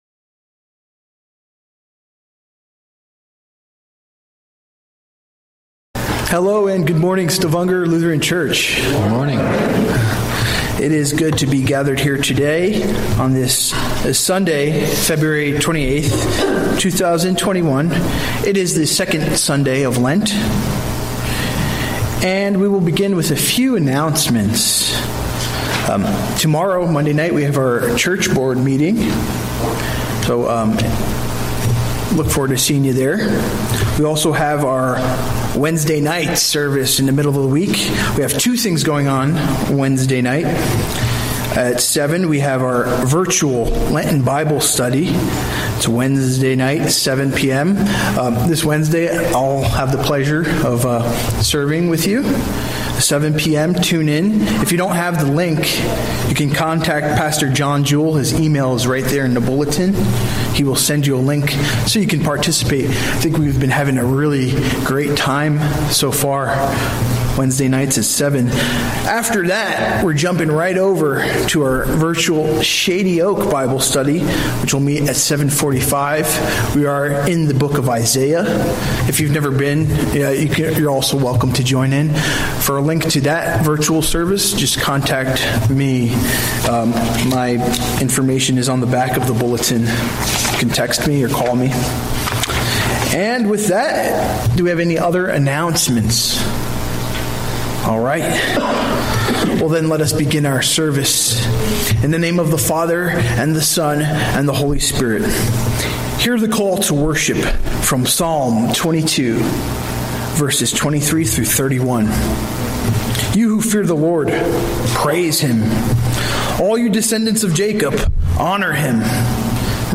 A message from the series "Sunday Worship." Cornerstone - Matthew 21:33-46
From Series: "Sunday Worship"